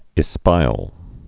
(ĭ-spīəl)